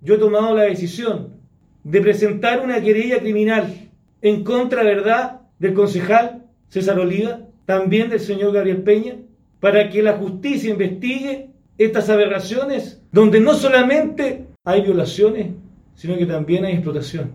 Tras conocer los antecedentes y reunirse con la víctima, el alcalde de Pitrufquén, Jorge Jaramillo (PPD), confirmó la existencia de la querella contra el edil.